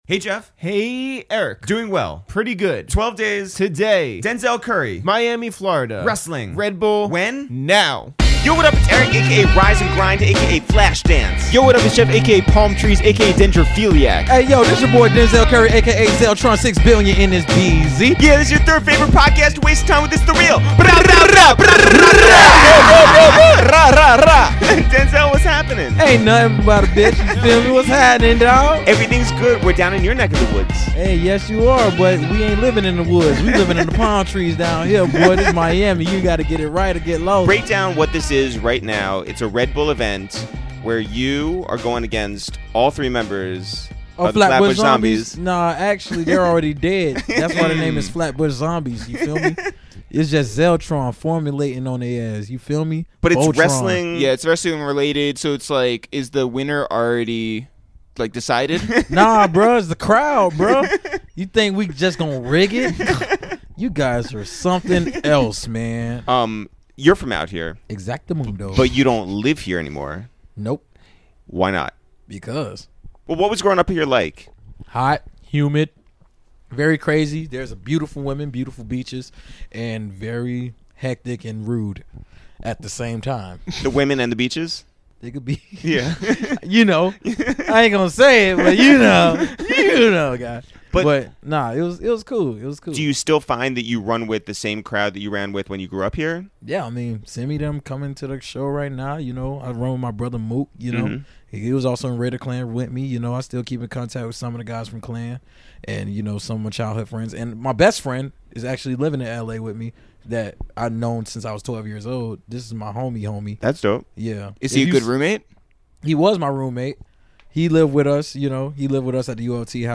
Today on A Waste of Time with ItsTheReal, for Day 3 of the #12DaysOfPodcasts, we went down to Miami to visit with Denzel Curry for a wide-ranging and personal conversation.